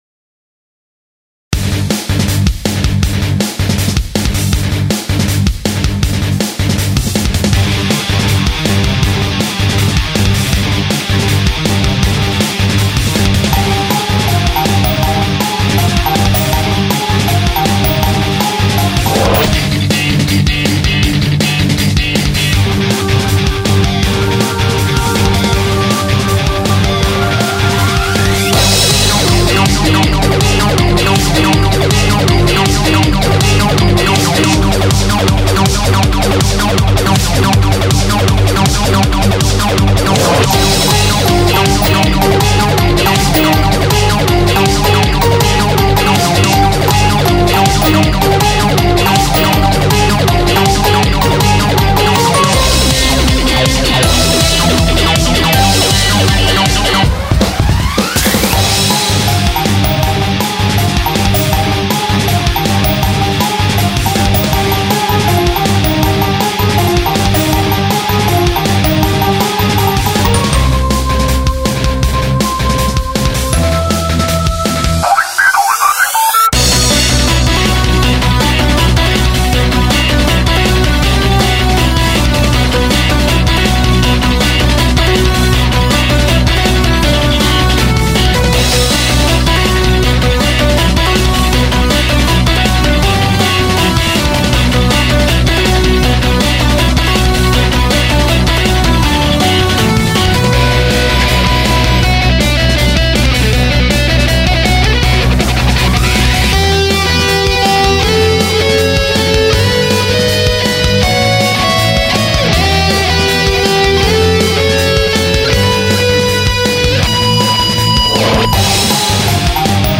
フリーBGM 戦闘曲